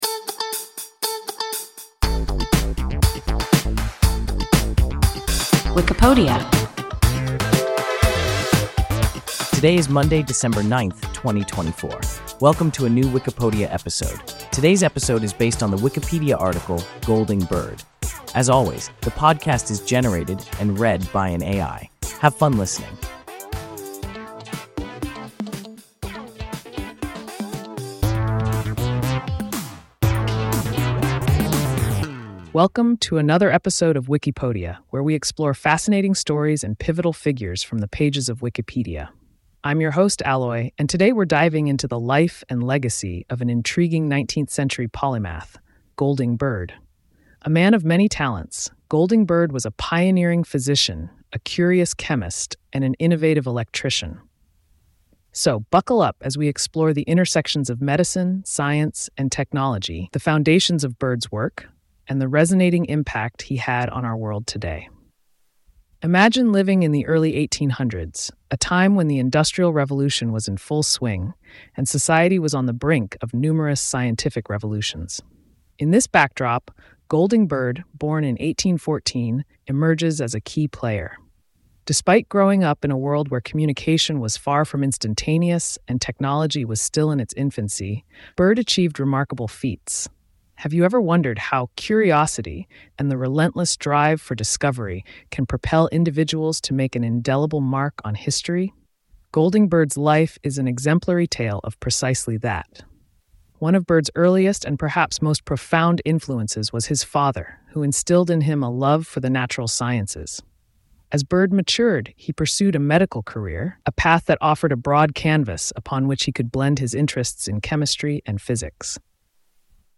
Golding Bird – WIKIPODIA – ein KI Podcast